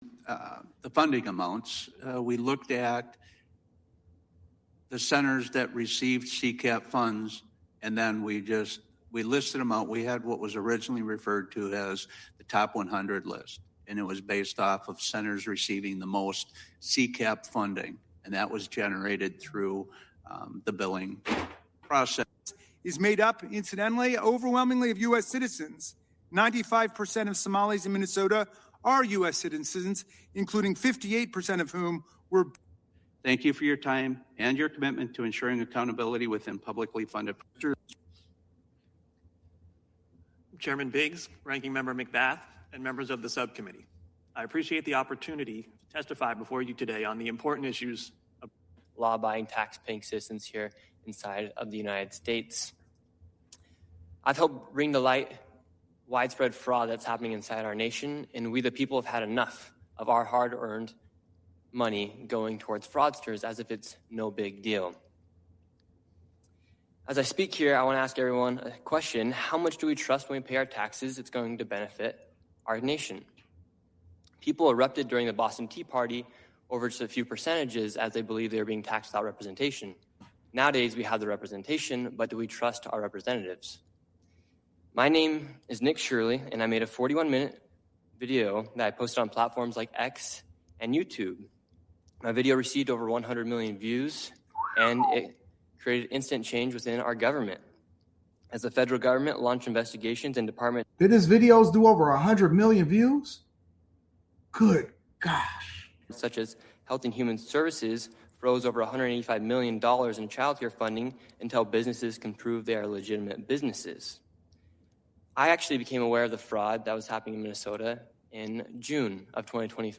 Testifies Before the House